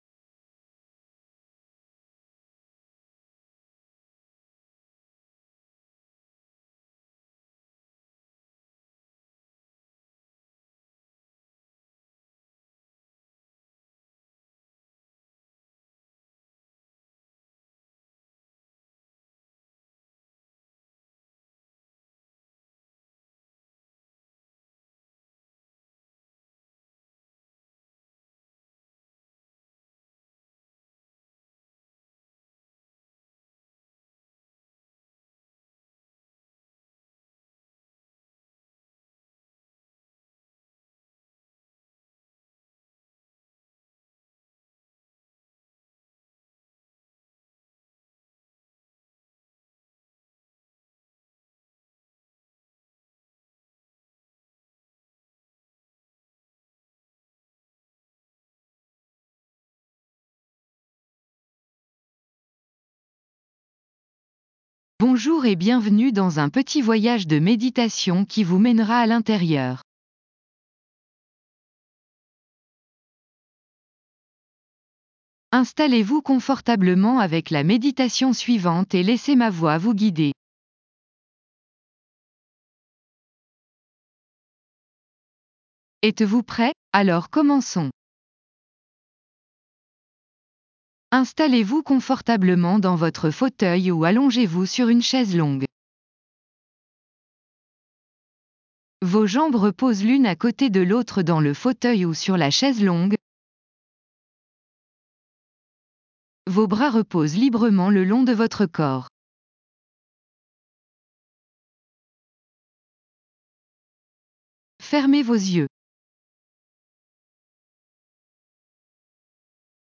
Restez concentré sur ces passages pendant que vous laissez la musique vous submerger.